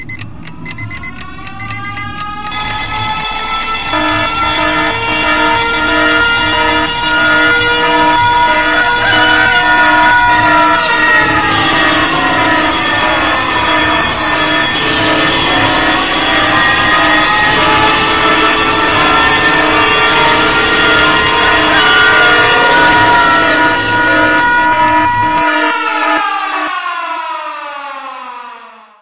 Рингтоны для будильника